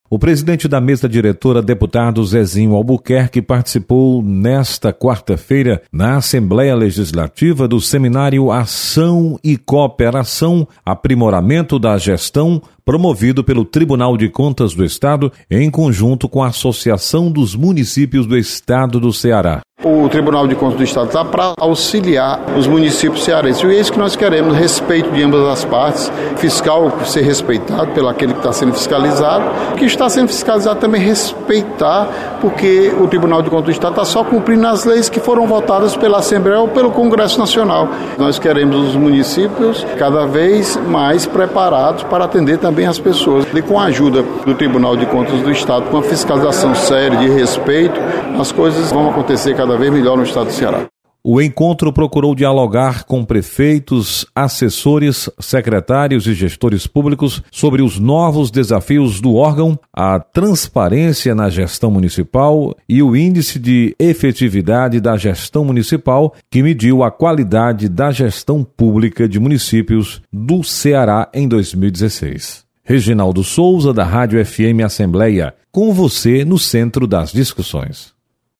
Presidente da Assembleia, deputado Zezinho Albuquerque participa de seminário para prefeitos e gestores municipais. Repórter